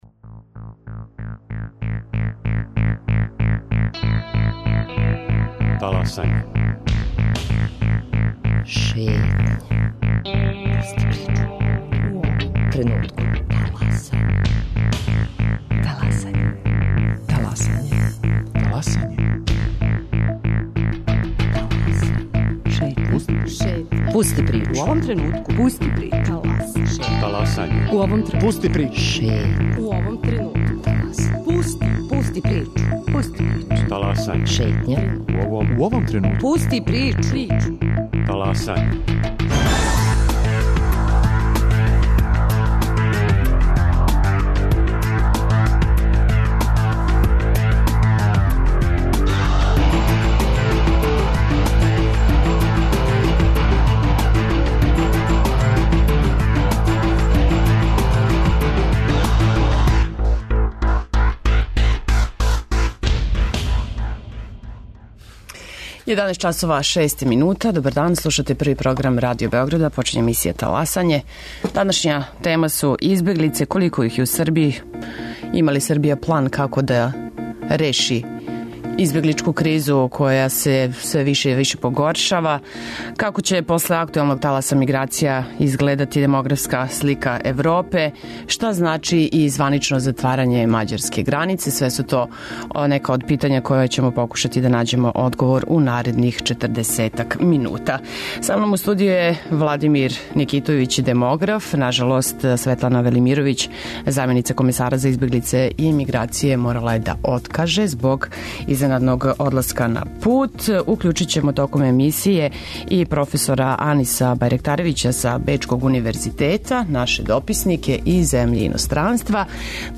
као и наши репортери.